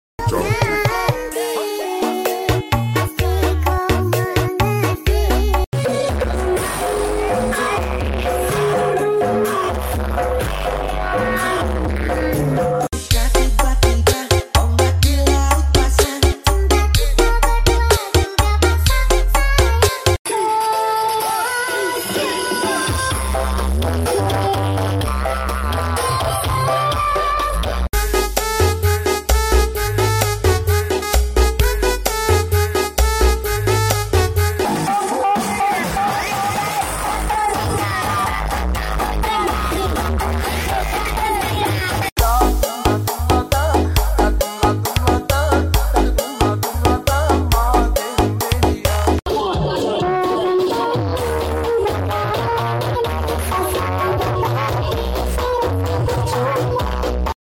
Pesona Karnaval Desa Sumberrejo Donorojo sound effects free download